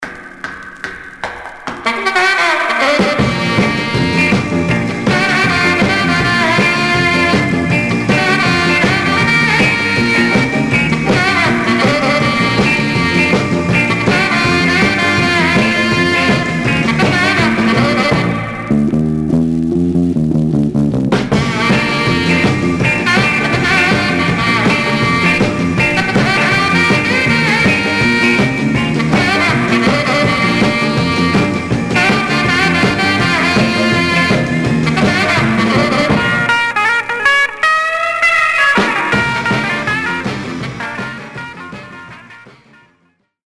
Surface noise/wear
Mono
R & R Instrumental